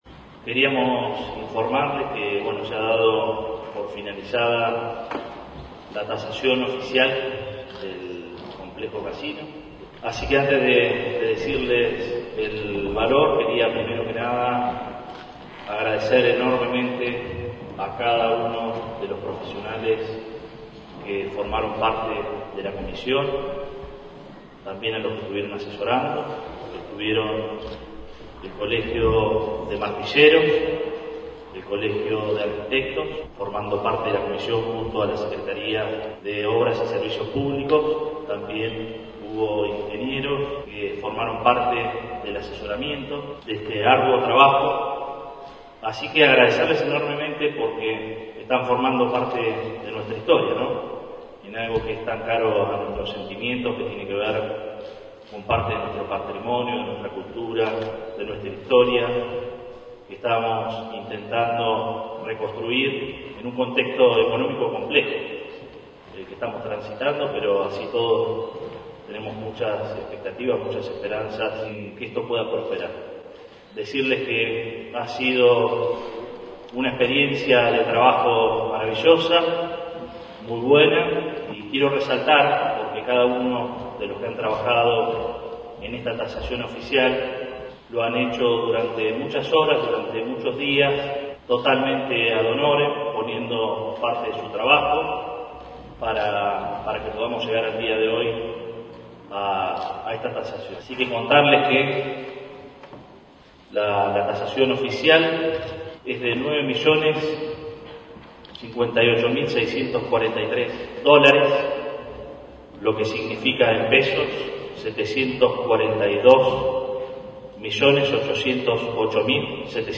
El intendente Arturo Rojas, acompañado por el Secretario de Planeamiento y Obras Públicas, Ricardo Carrera, y representantes de los colegios profesionales que participaron de la comisión tasadora, anunció esta tarde en conferencia de prensa la cotización oficial del Casino, que será adjuntada al expediente que bajó al Concejo Deliberante buscando la rápida aprobación de un Concurso de Proyectos que tiene por objetivo la reconstrucción plena del complejo.